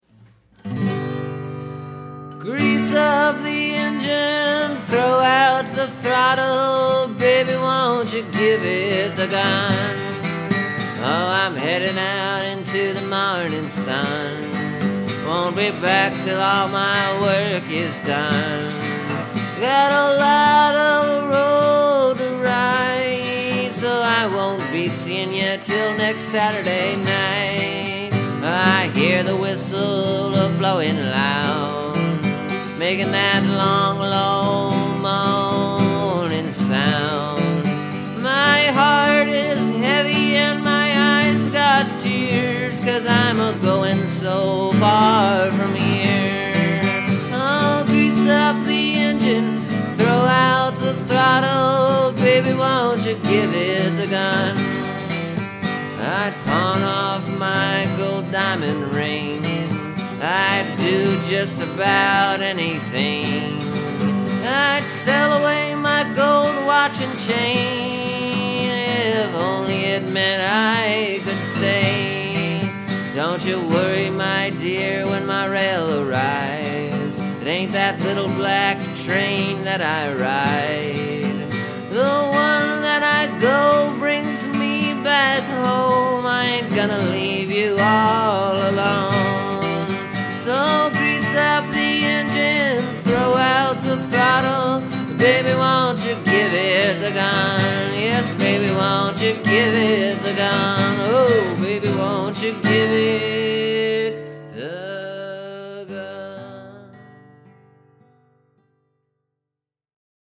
This song is pretty much your standard folk train song.